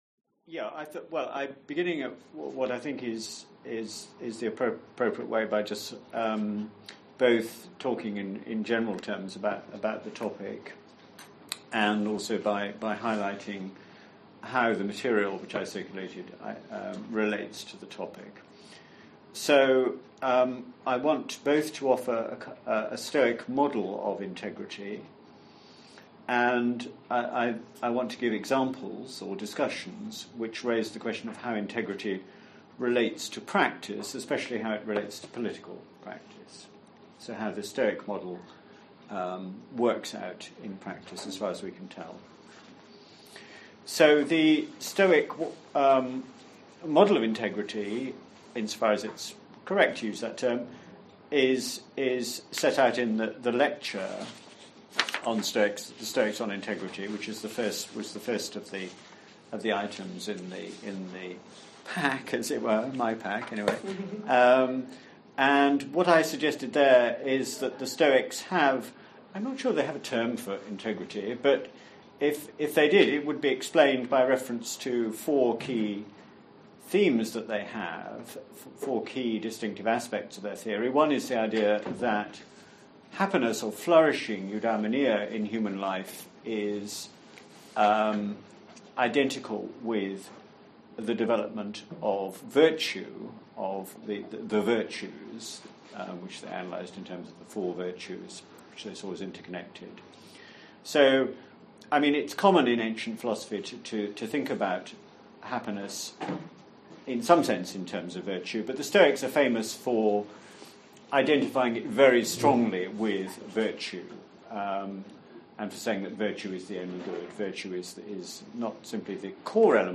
Introductory lecture